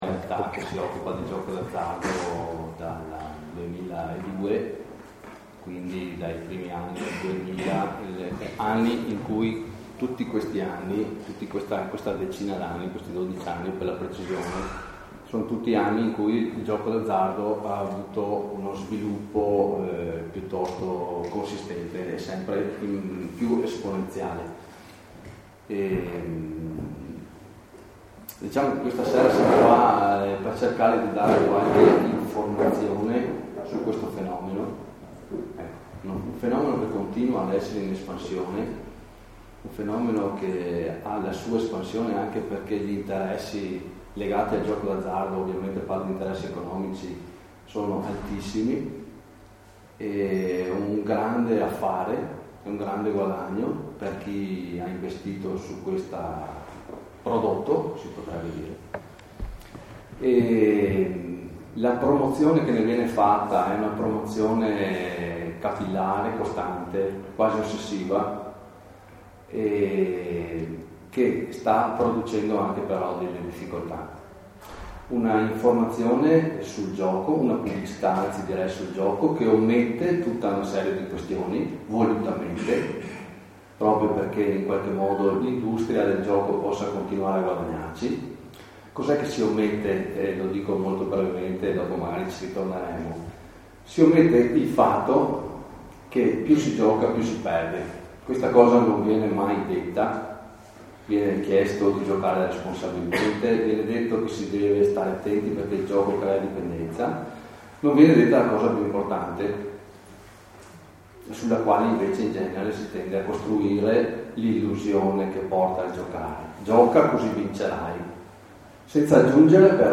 Psicologa e psicoterapeuta Una cinquantina di persone hanno assistito giovedì 20 marzo alla serata tenuta dal dott.